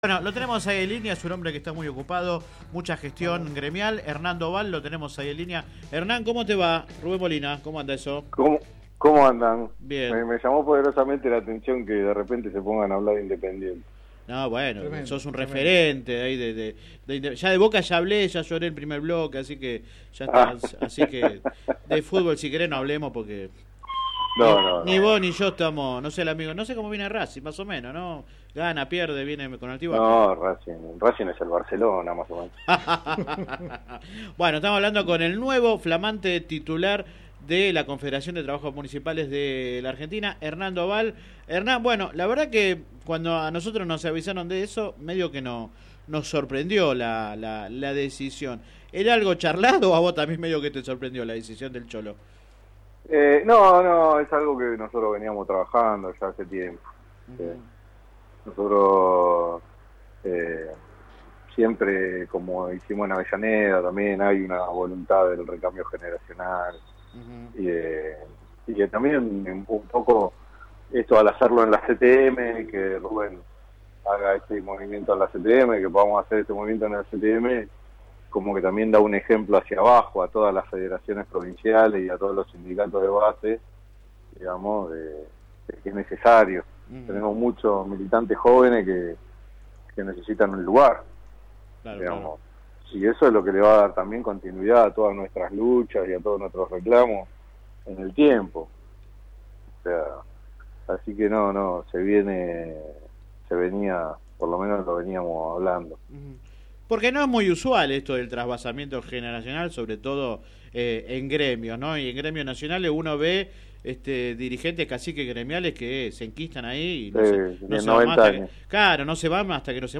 En una extensa entrevista se refirió a los problemas de los empleados del sector, el proceso de recambio dirigencial en el gremio y su relación con Jorge Ferraresi.